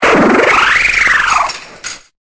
Cri de Cliticlic dans Pokémon Épée et Bouclier.